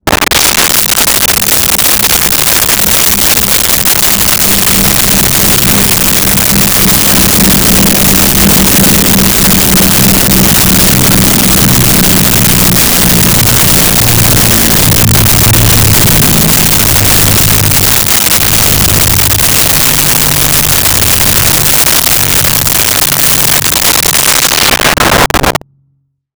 Propeller Plane By
Propeller Plane By.wav